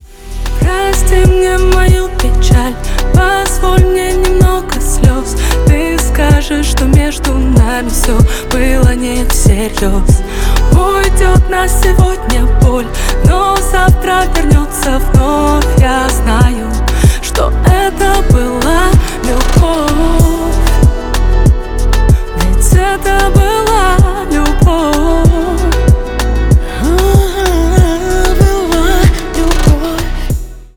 грустные
дуэт
поп